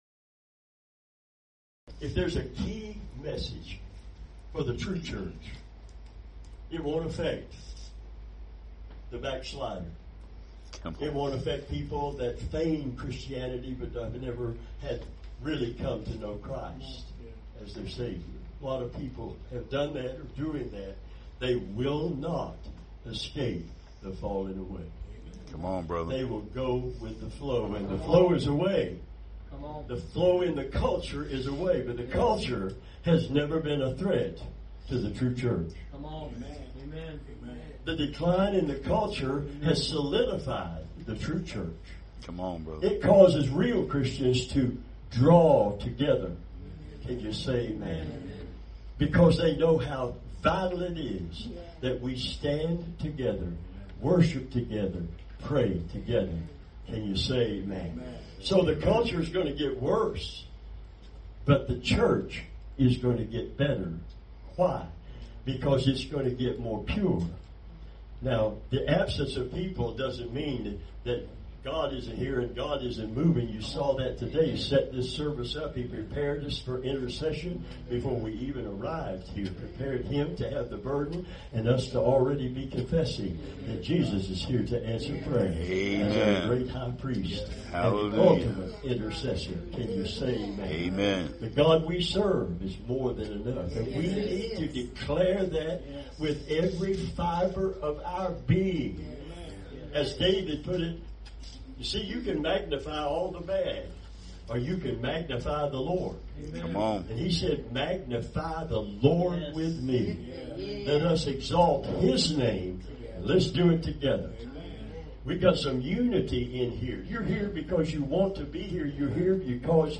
Revival Sermons